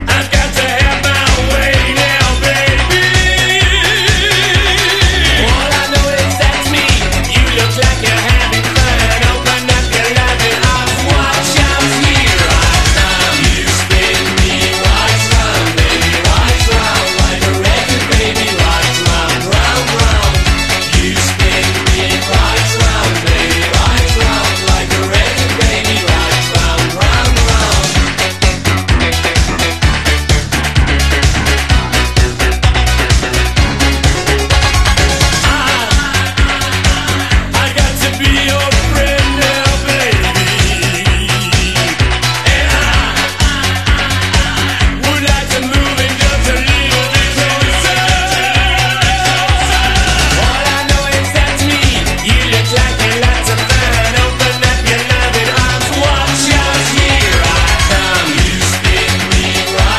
Screwing around removing hard drive